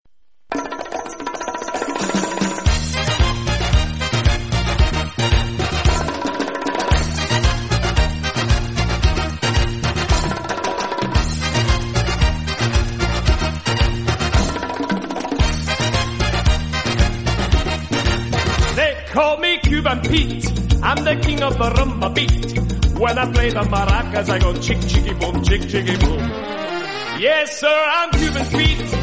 P O L I C E